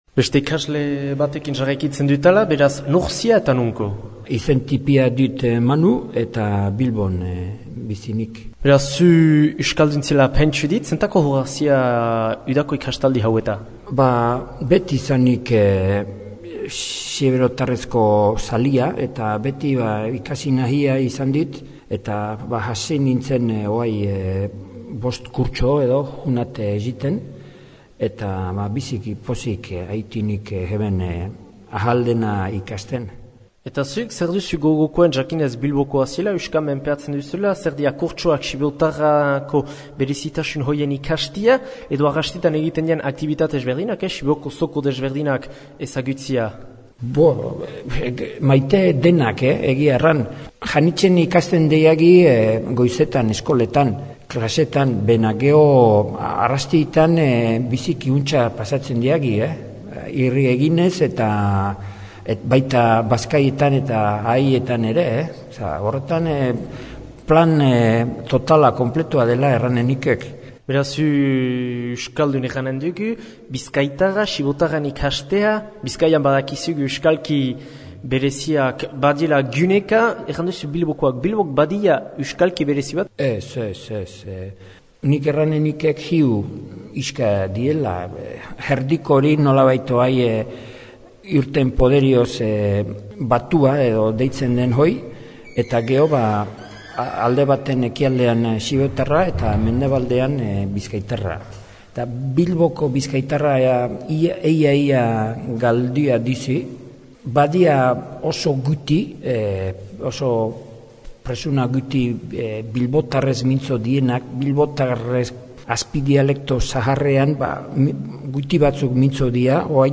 Entzün ikastaldian den Bilbotar bat :
ikastaldia2012bilbotarbat.mp3